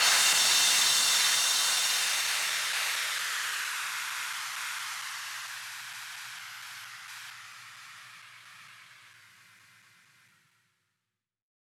VTS1 Lovely Day Kit Drums & Perc
VTS1 Lovely Day Kit 134BPM Crash.wav